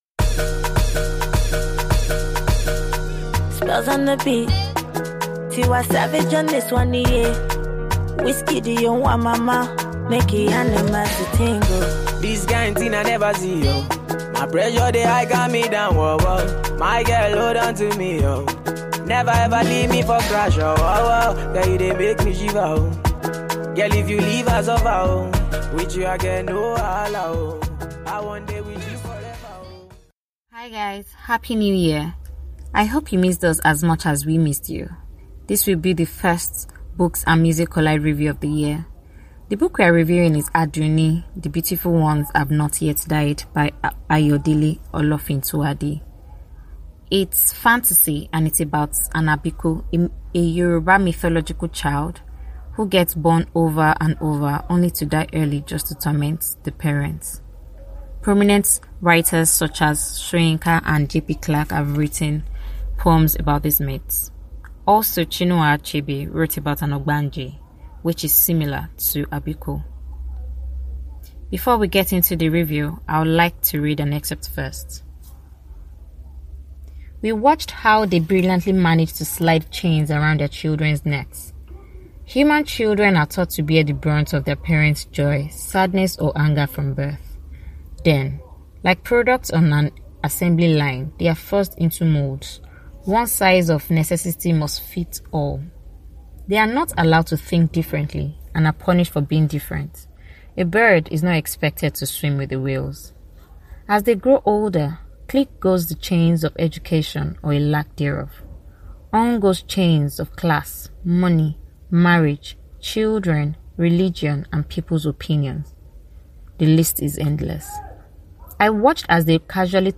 Review